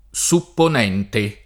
DOP: Dizionario di Ortografia e Pronunzia della lingua italiana
supponente